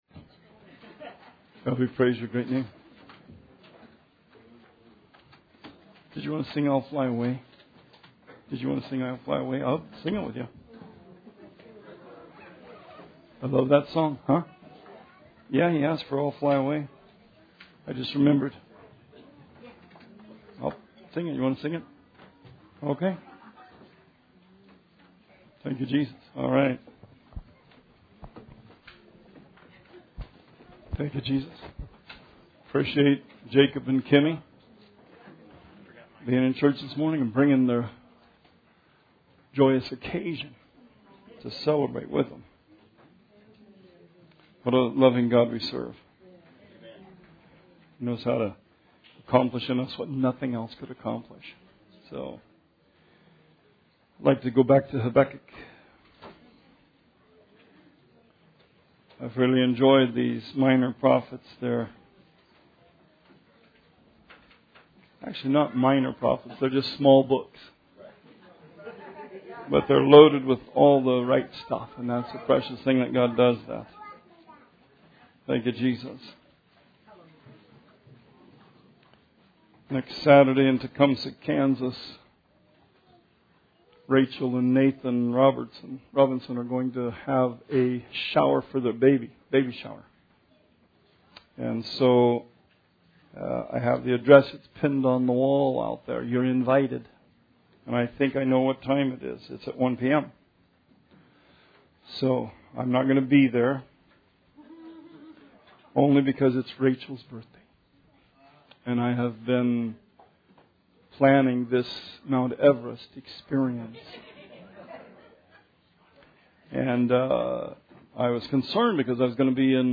Sermon 10/29/17